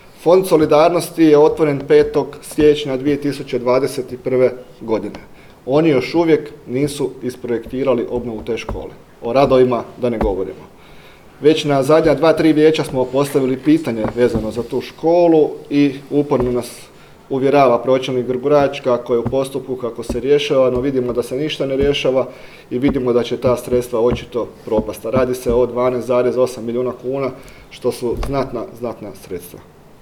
na danas održanoj tiskovnoj konferenciji